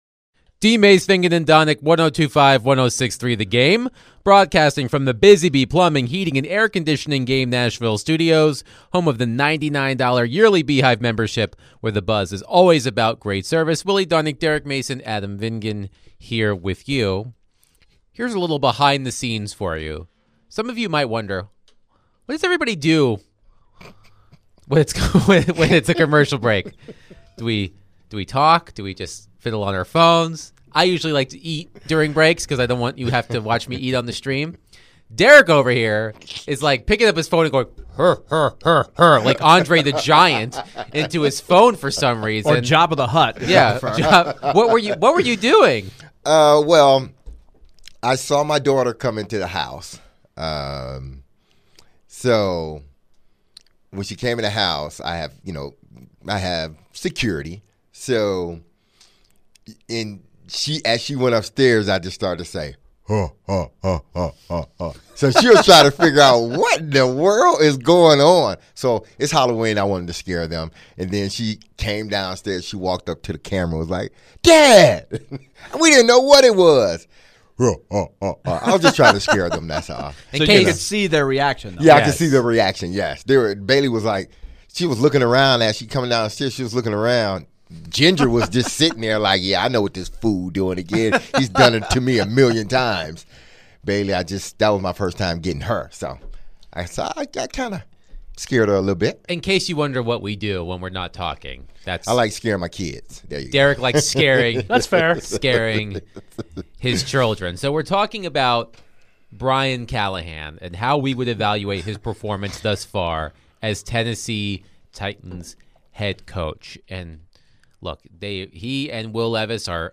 answered questions from callers and texters